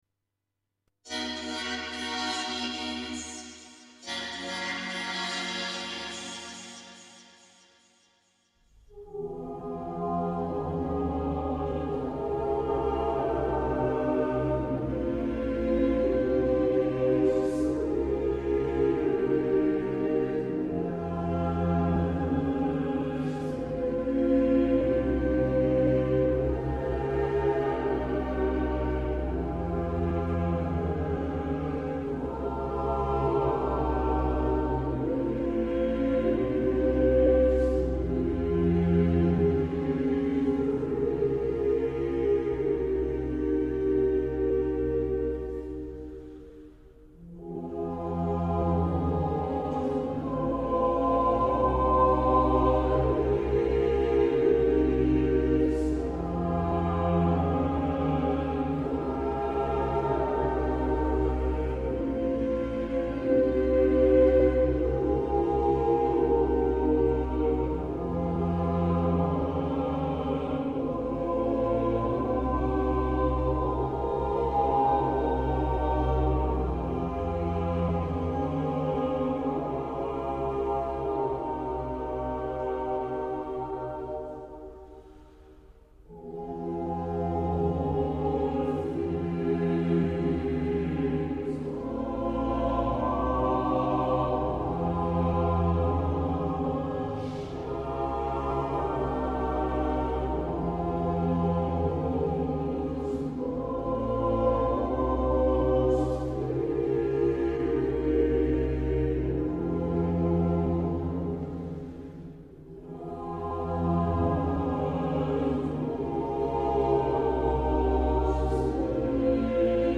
Ambient Avant-Garde Classical